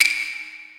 soft-hitwhistle.wav